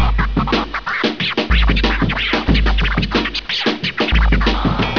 Se vuoi sentire un pò di scratch clicca qui!!!